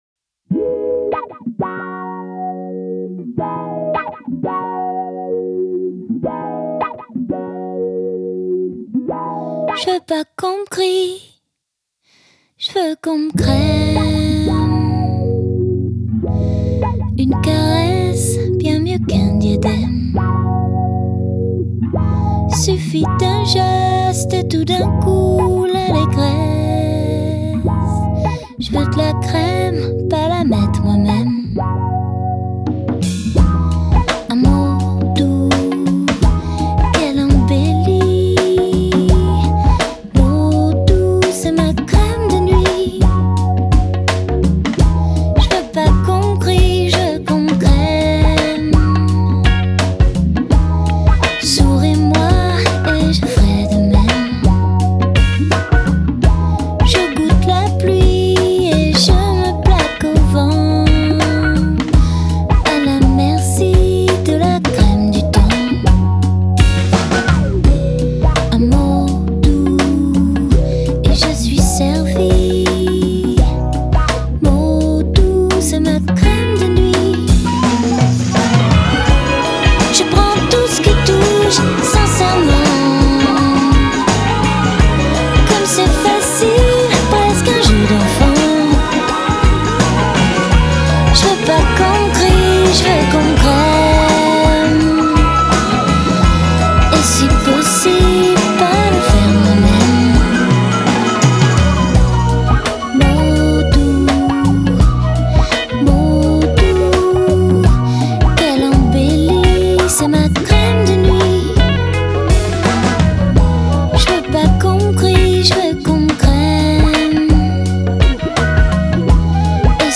Жанр: Pop, Chanson